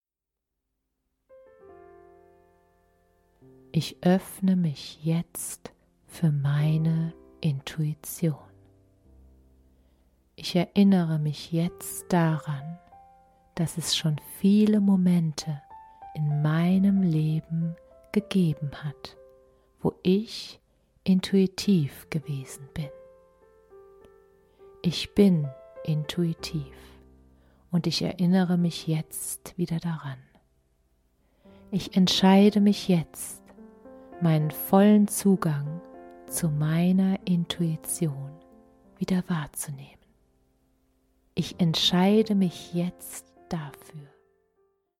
Meditationen für eine starke Intuition